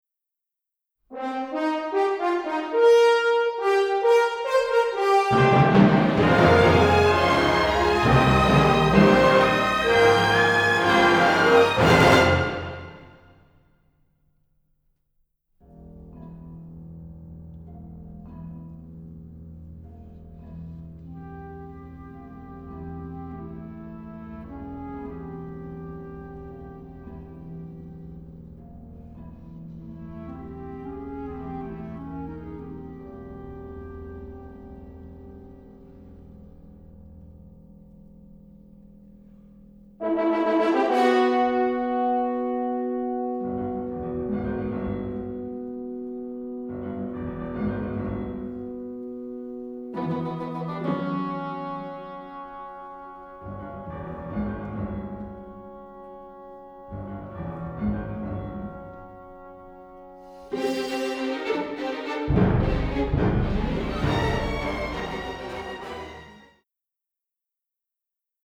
crisp recording